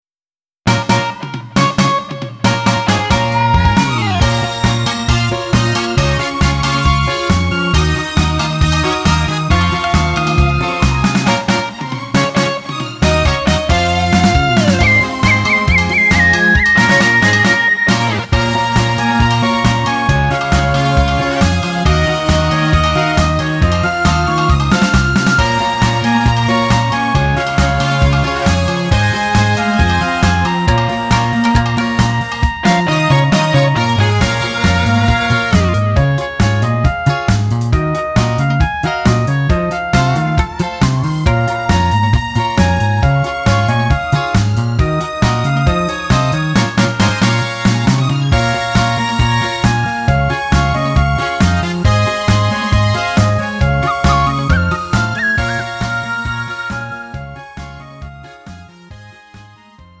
음정 여자키 3:17
장르 가요 구분 Pro MR
Pro MR은 공연, 축가, 전문 커버 등에 적합한 고음질 반주입니다.